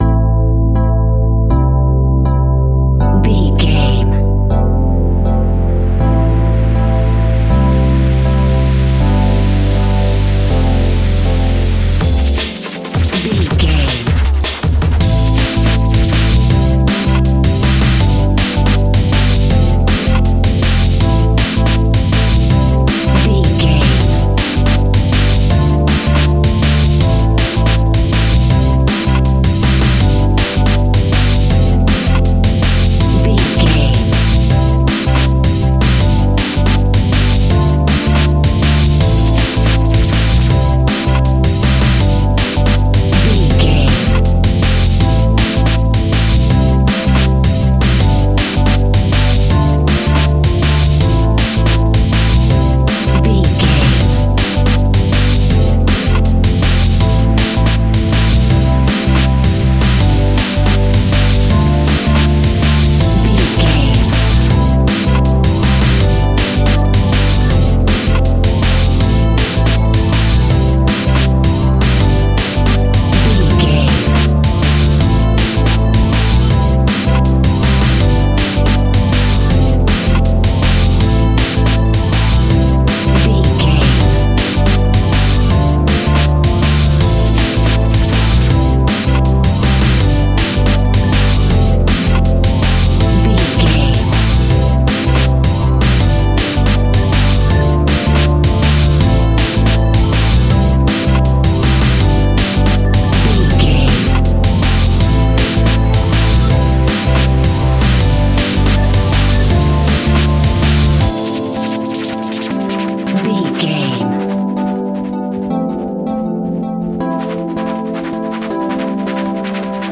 Aeolian/Minor
Fast
energetic
hypnotic
frantic
drum machine
synth leads
electronic music
techno music
synth bass
synth pad